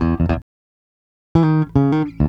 Bass Lick 34-08.wav